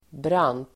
Uttal: [bran:t]